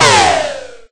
Shot3.ogg